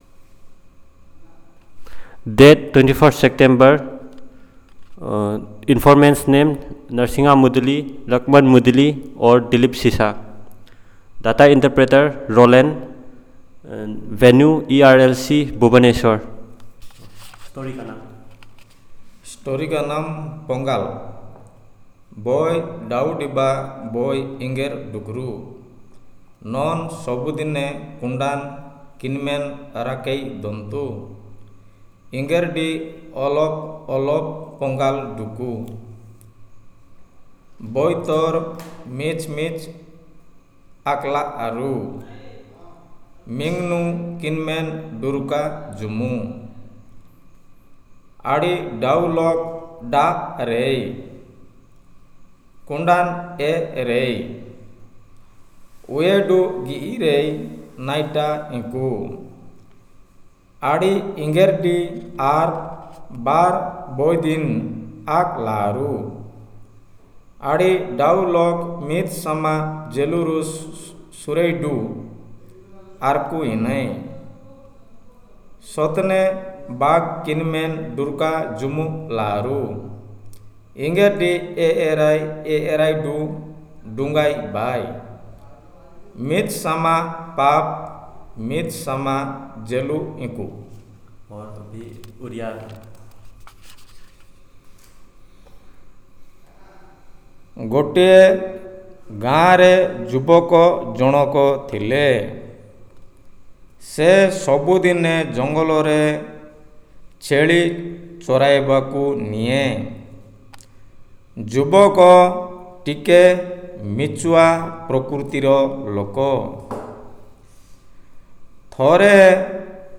Elicitation of Story